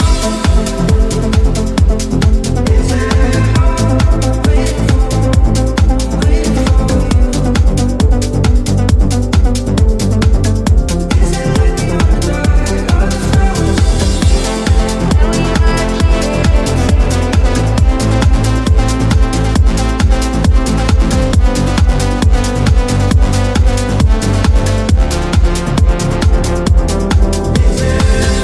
Genere: house,chill,deep,remix,hit